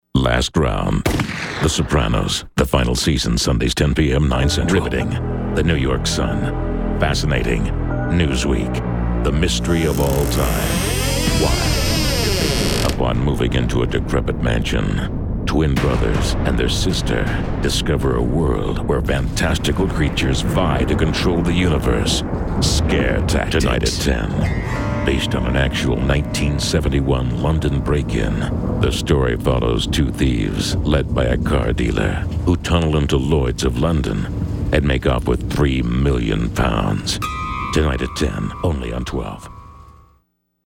Male American V.O. talent. Warm, Deep, Big, Smooth
Sprechprobe: Werbung (Muttersprache):